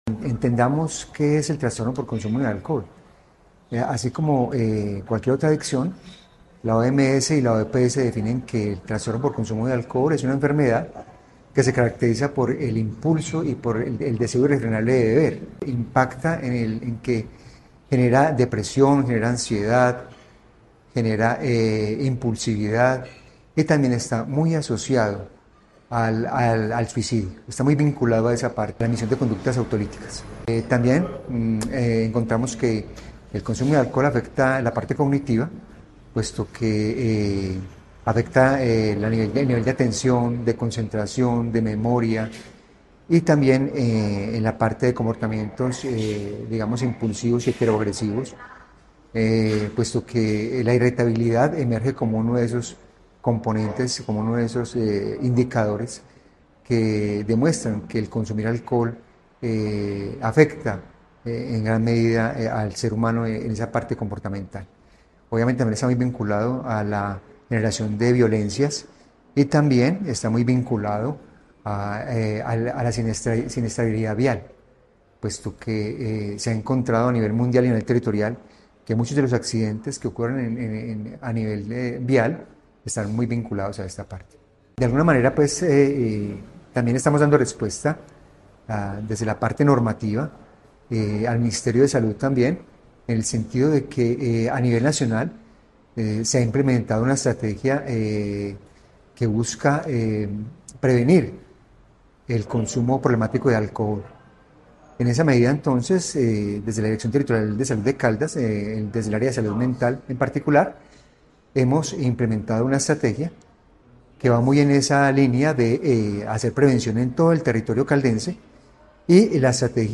CON EUCARISTÍA EN LA CATEDRAL BASÍLICA NUESTRA SEÑORA DEL ROSARIO DE MANIZALES, ADULTOS MAYORES DE CALDAS CONTINÚAN CELEBRANDO SU MES